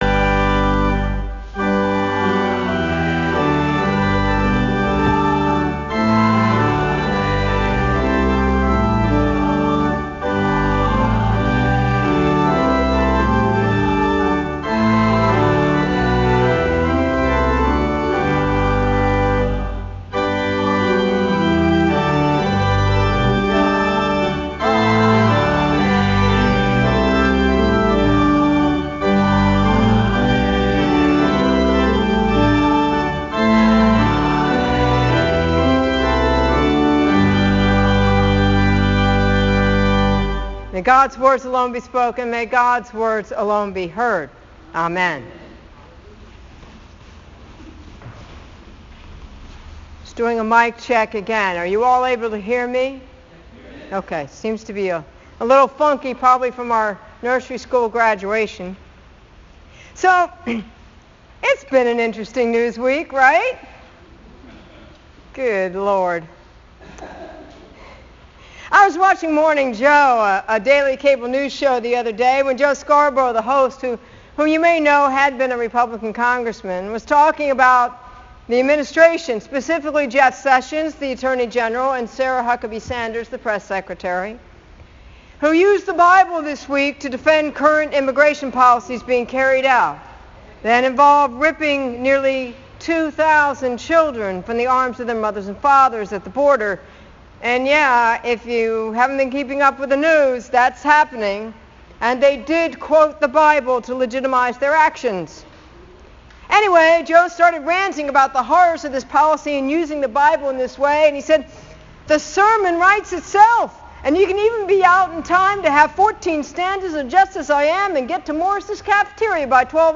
Now, as I mentioned at the start of this sermon, right now, our government is attempting to use the bible, specifically Romans 13:1, to justify the horrific actions at our borders.